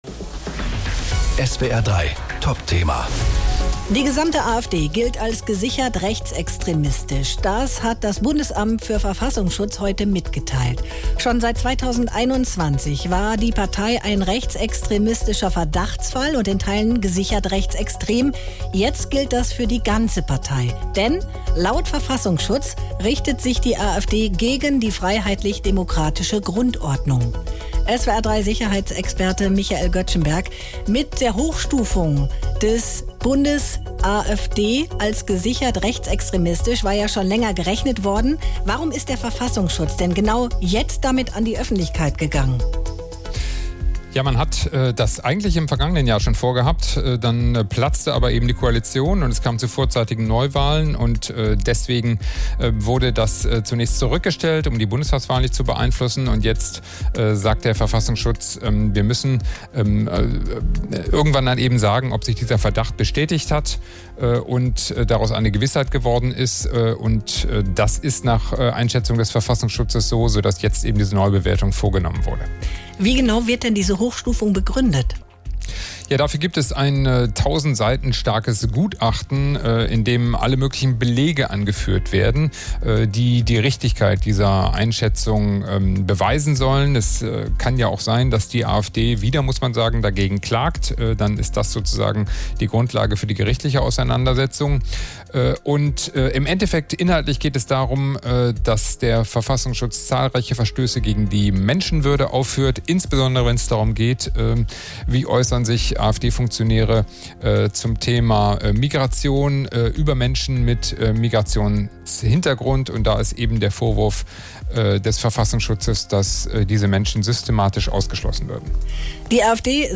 Welche Folgen die Einstufung des Verfassungsschutzes jetzt für die AfD und die Zusammenarbeit mit anderen Parteien hat, hört ihr im ganzen Gespräch: